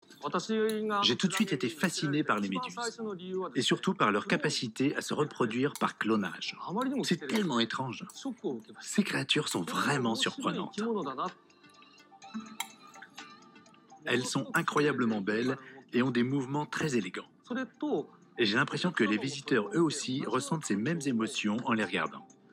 LA VIE EN FLUO - Briller pour s'unir - Voice over ARTE